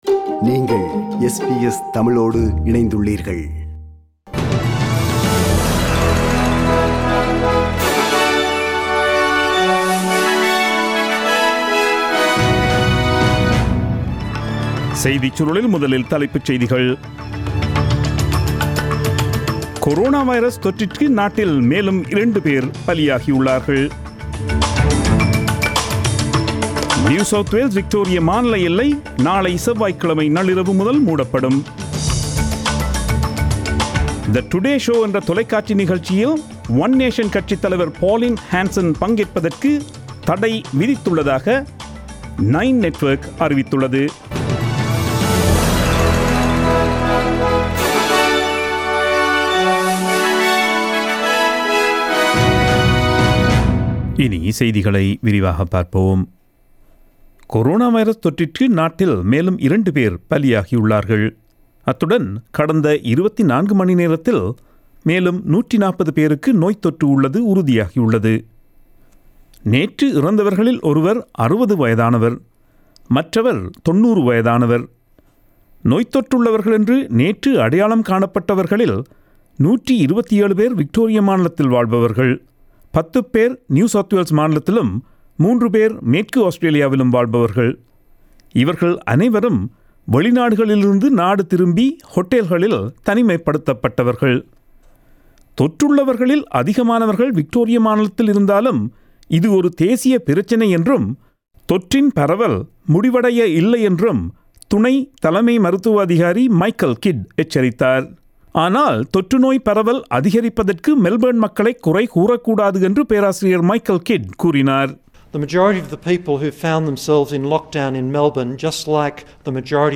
Australian news bulletin aired on Monday 06 July 2020 at 8pm.